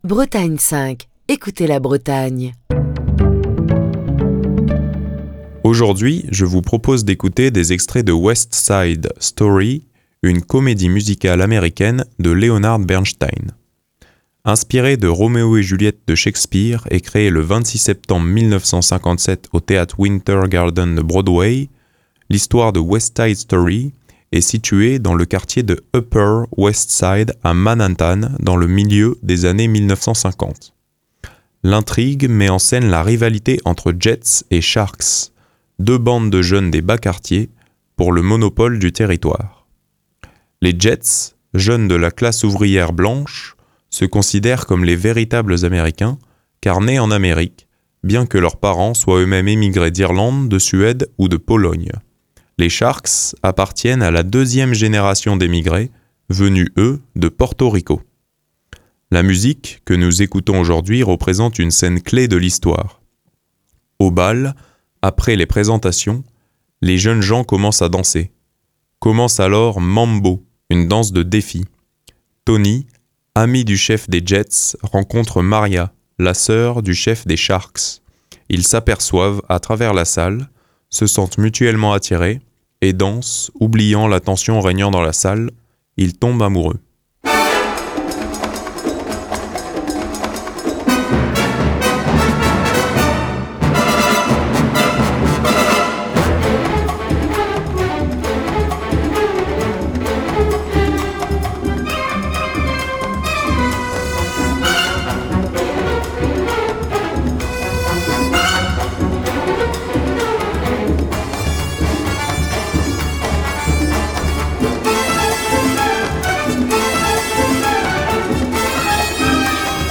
une danse de défi.
la danse endiablée